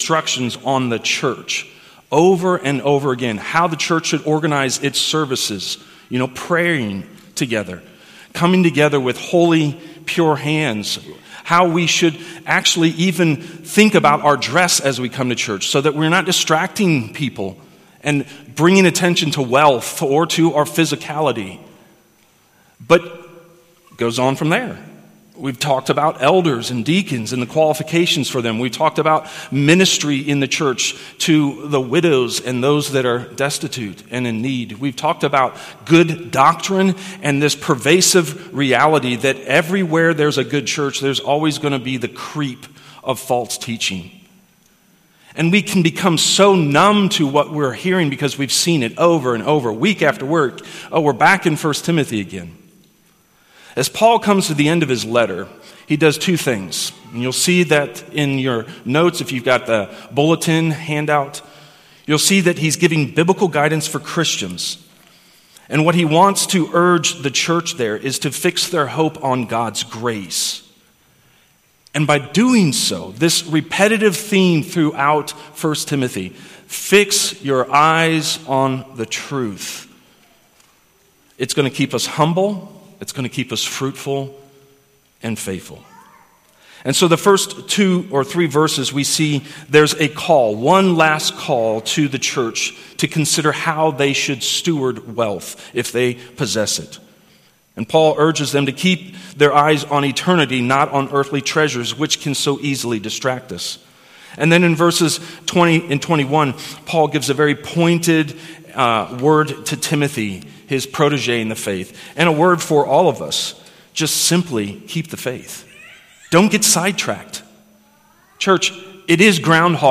Sunday AM Worship | January 11th